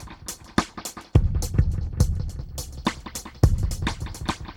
Index of /musicradar/dub-drums-samples/105bpm
Db_DrumsB_EchoKit_105-03.wav